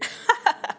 haha_3.wav